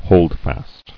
[hold·fast]